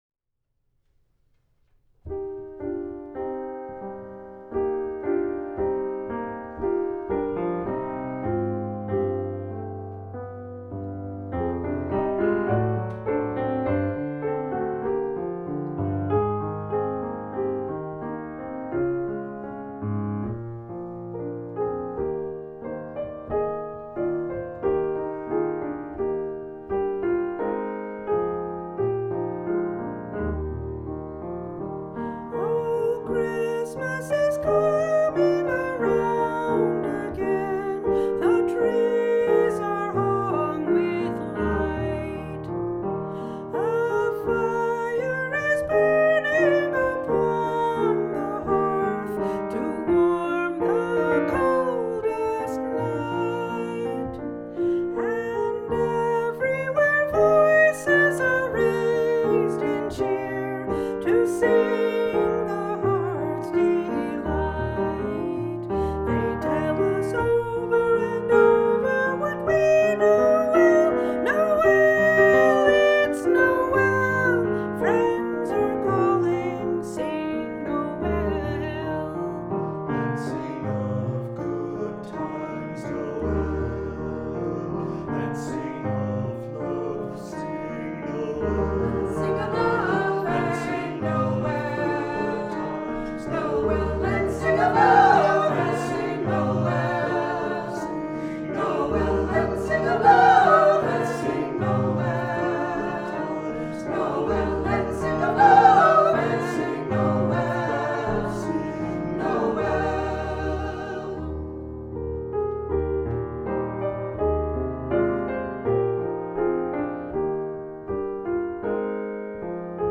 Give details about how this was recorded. Recorded live